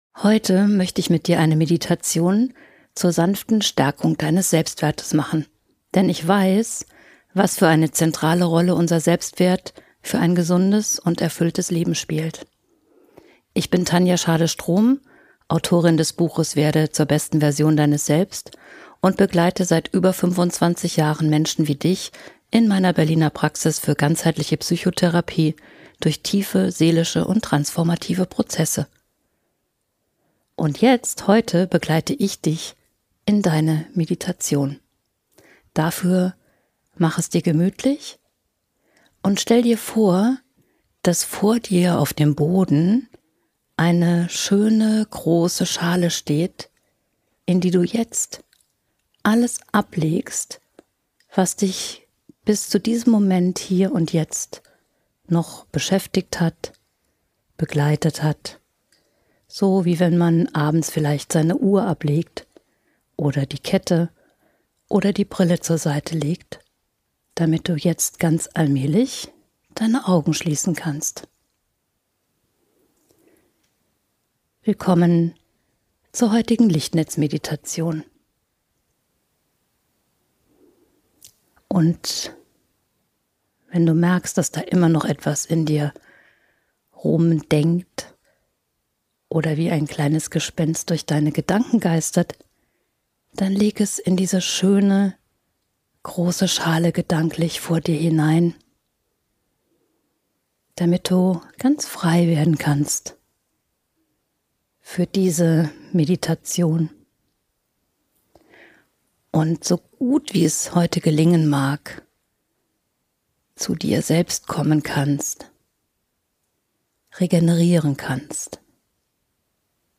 Diese Selbstfürsorge-Meditation hilft dir, mit deinem Innersten in Kontakt zu kommen, deinen Selbstwert zu stärken und dir deiner Einzigartigkeit bewusst zu werden.